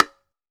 Index of /90_sSampleCDs/AKAI S6000 CD-ROM - Volume 5/Cuba2/STEREO_BONGO_2